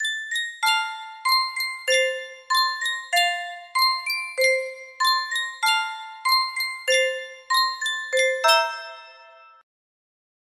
Sankyo Miniature Music Box - Billy Boy FFR music box melody
Sankyo Miniature Music Box - Billy Boy FFR
Full range 60